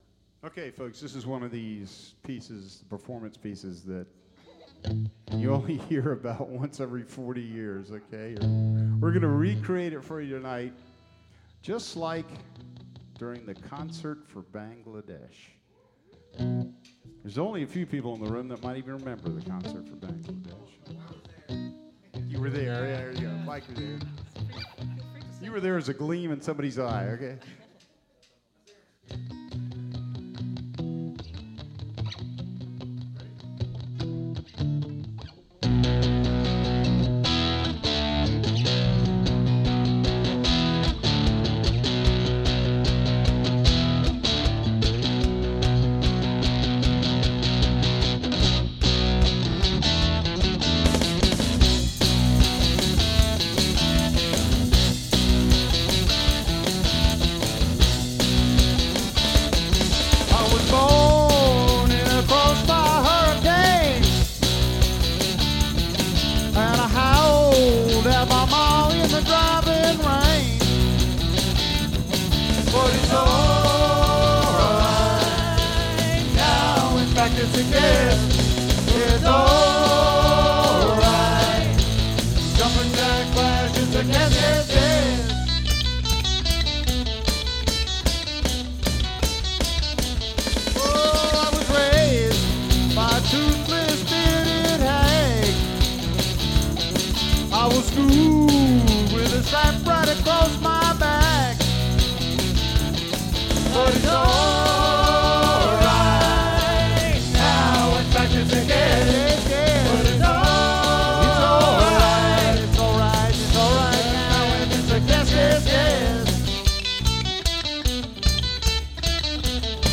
was a rock & roll band from Chesapeake and Virginia Beach